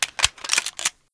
wpn_markscarb_reload.wav